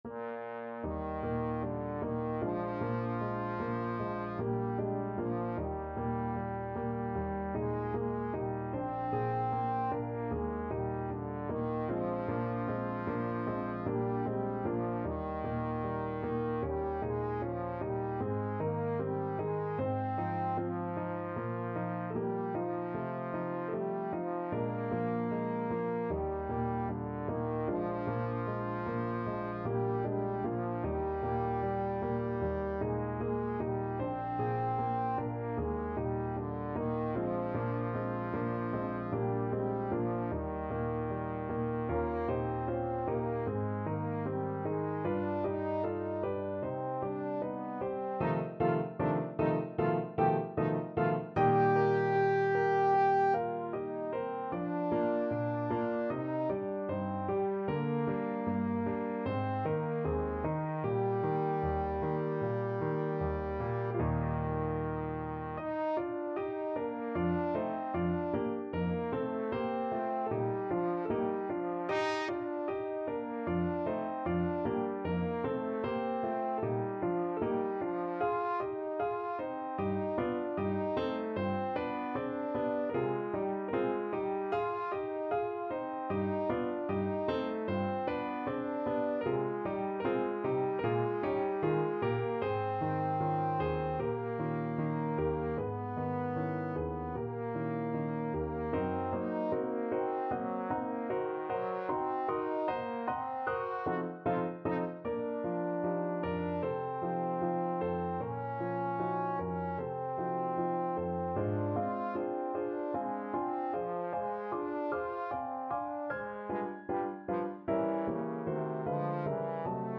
Trombone
Eb major (Sounding Pitch) (View more Eb major Music for Trombone )
2/4 (View more 2/4 Music)
Lento ma non troppo = c.76
Classical (View more Classical Trombone Music)